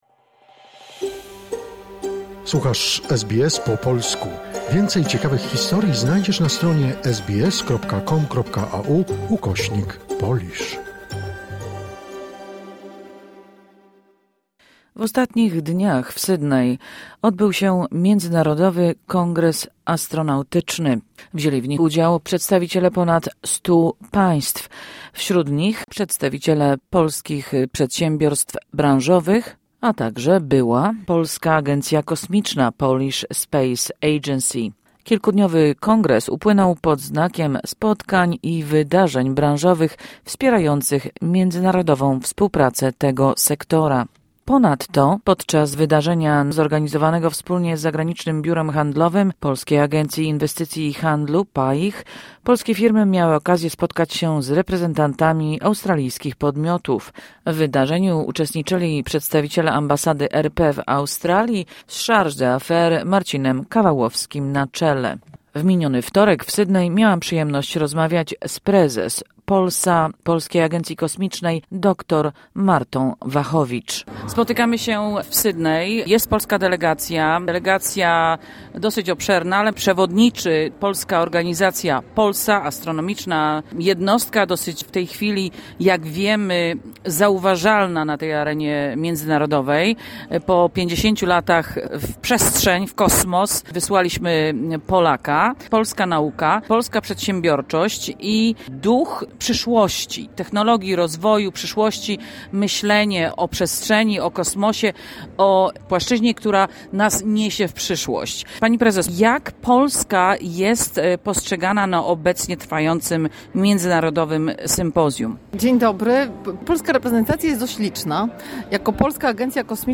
Prezes Polskiej Agencji Kosmicznej POLSA dr Marta Wachowicz mówi o międzynarodowym kongresie sektora astronautycznego, w którym wzięła udział reprezetacja polskich przedsiębiorców i POLSA. Jakie osiagnięcia i dorobek ma Polska w tej dziedzinie na arenie międzynarodowej?